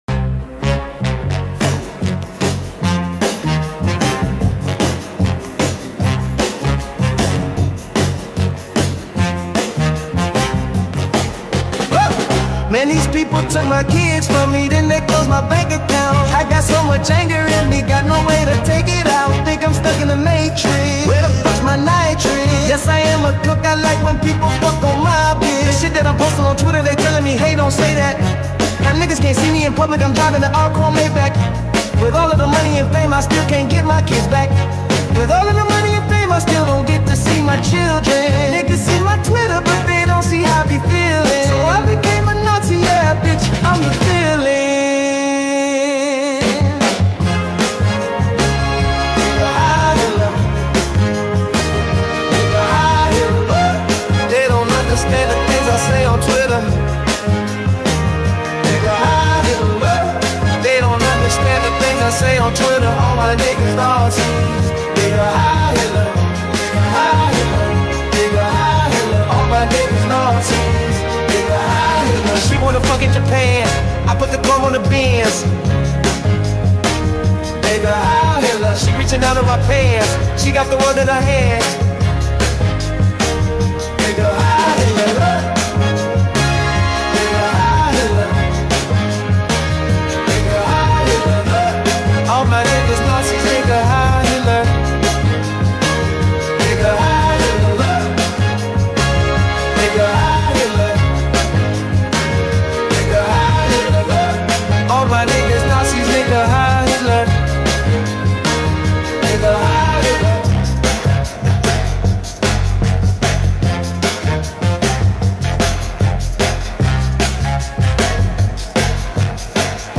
ye-hh-60s-motown-mix-mp8WrZj6jvSpEqLJ.mp3